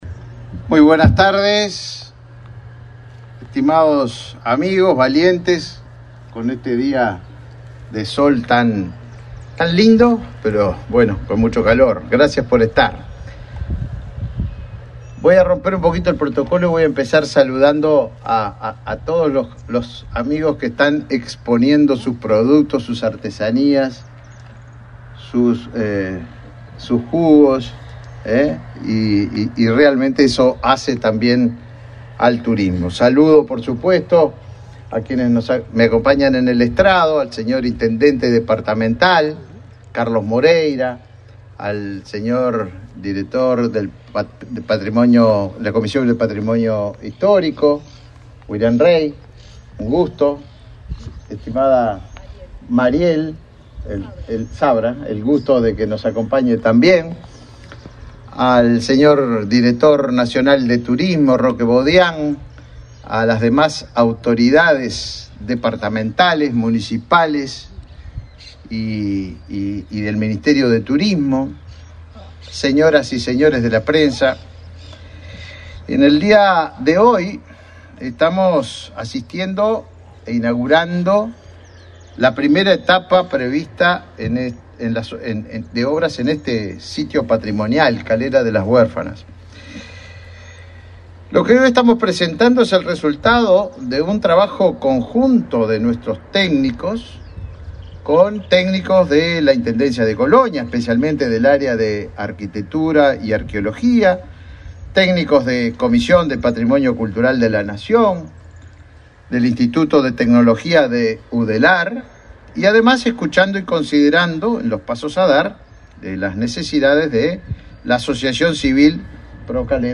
Acto por inauguración de obras en Calera de las Huérfanas
Participaron el ministro Tabaré Viera y el director general de la Comisión del Patrimonio William Rey.